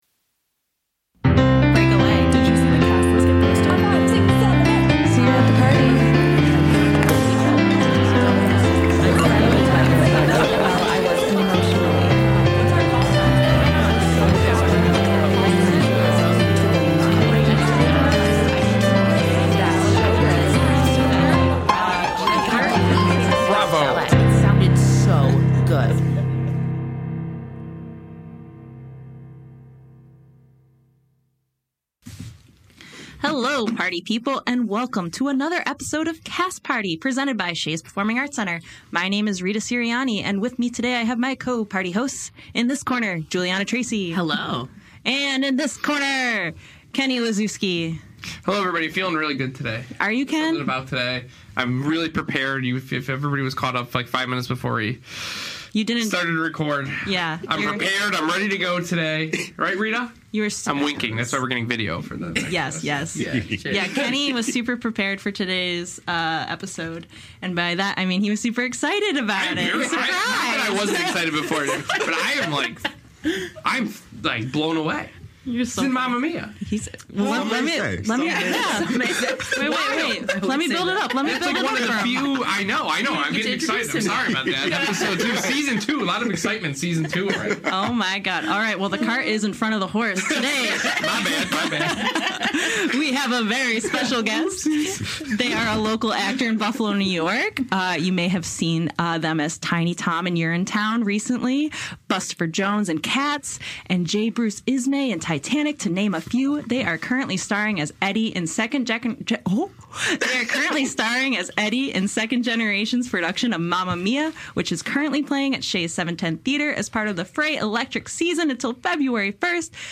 Honey, honey… how we love this interview!